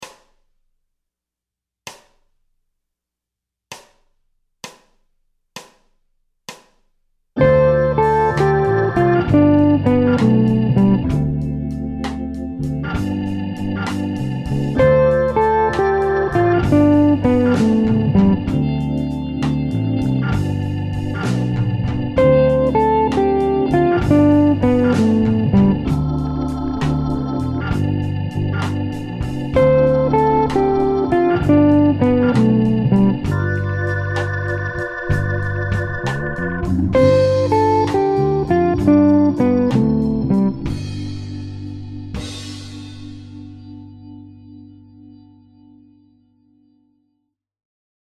Phrase 07 – Cadence ii V7 en Majeur
La phrase utilise uniquement le ‘Motif 5 3 2 1’